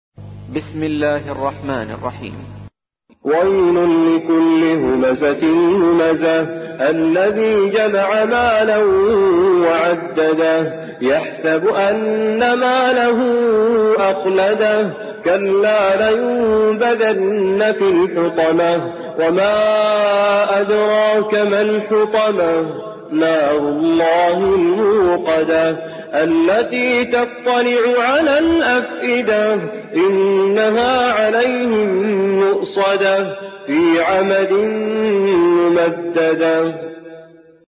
تحميل : 104. سورة الهمزة / القارئ توفيق الصايغ / القرآن الكريم / موقع يا حسين